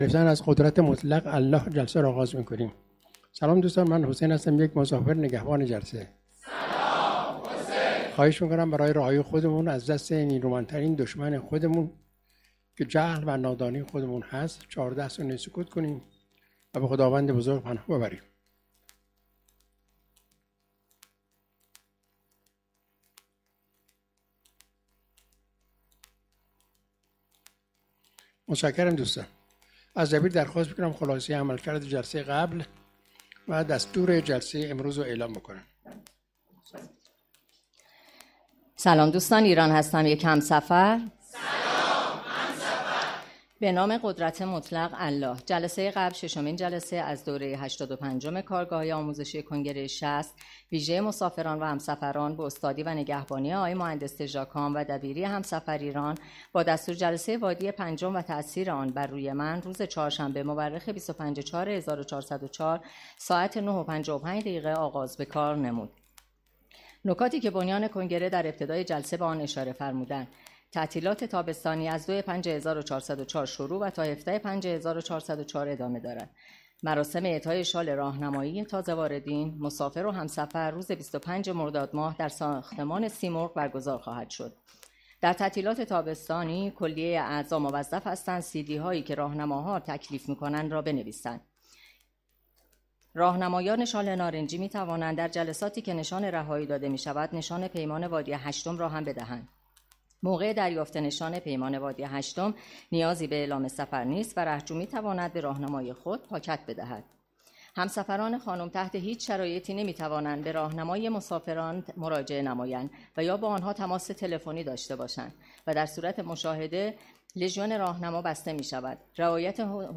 کارگاه آموزشی جهان‌بینی؛تعطیلات تابستانی